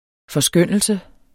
Udtale [ fʌˈsgœnˀəlsə ]